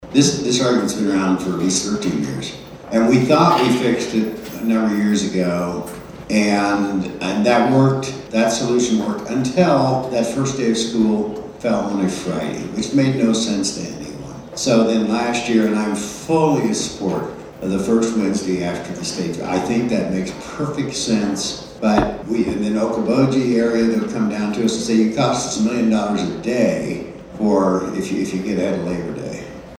The Mahaska Chamber hosted its second Coffee and Conversation event of 2025 on Saturday morning at Smokey Row Coffee in Oskaloosa.
On the topic of school start dates, Senator Ken Rozenboom said that it’s a conversation that is had virtually every year, with schools on one side and tourism on the other.